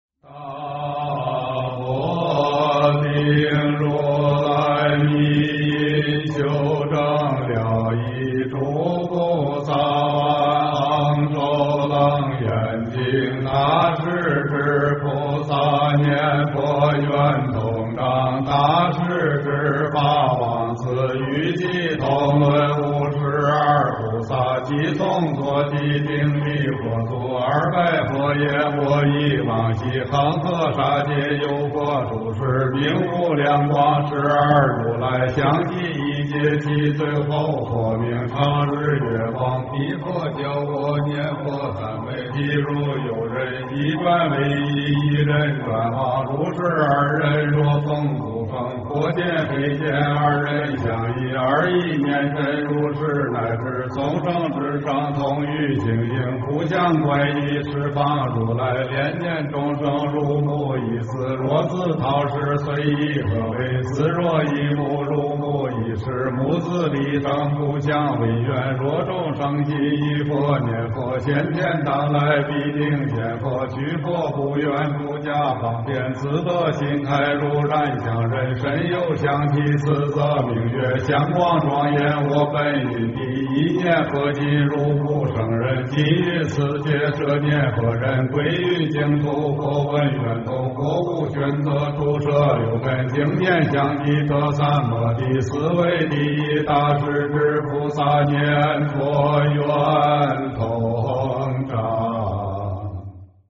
大势至菩萨念佛圆通章 - 诵经 - 云佛论坛